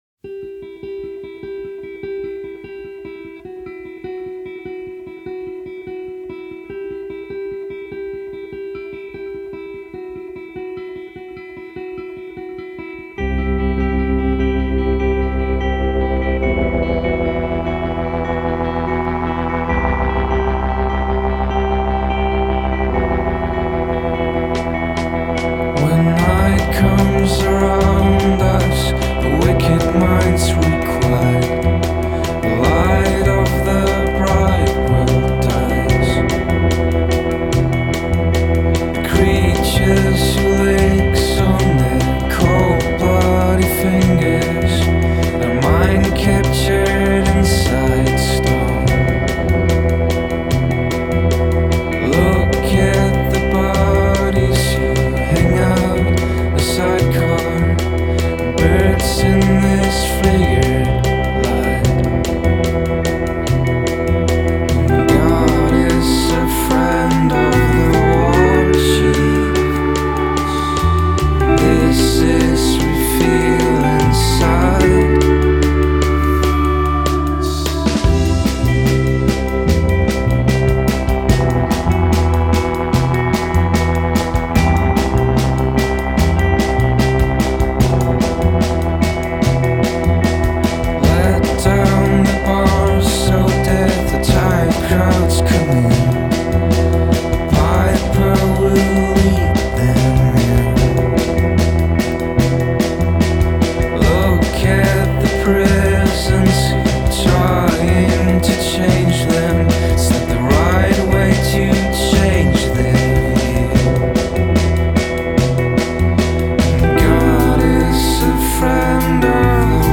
Gesang / Gitarre
Orgel / Synthesizer
Bass / Gesang / Piano
Schlagwerk